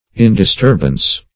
Meaning of indisturbance. indisturbance synonyms, pronunciation, spelling and more from Free Dictionary.
Search Result for " indisturbance" : The Collaborative International Dictionary of English v.0.48: Indisturbance \In`dis*turb"ance\, n. Freedom from disturbance; calmness; repose; apathy; indifference.